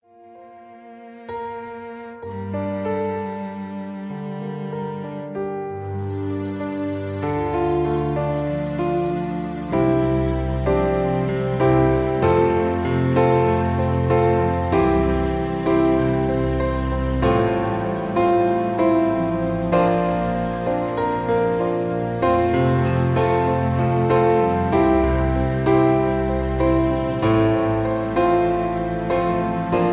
(Key-Dm)